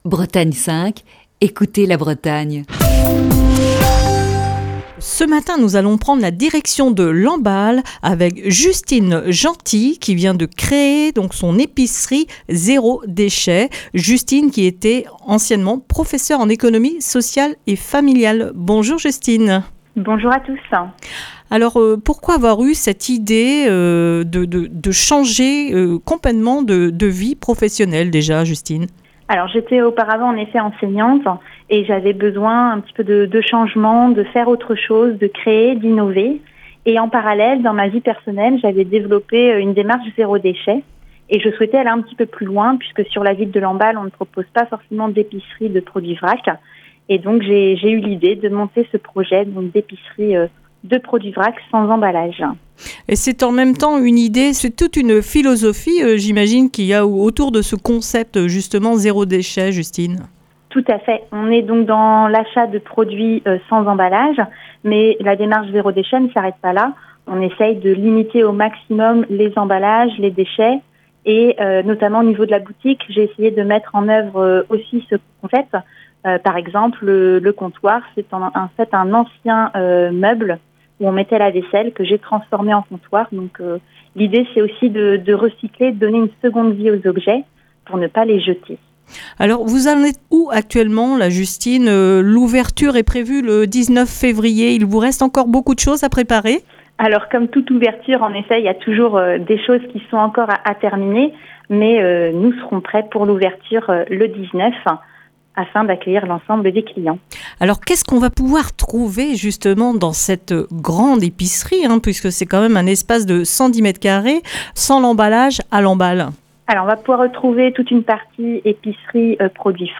Dans le coup de fil du matin de ce lundi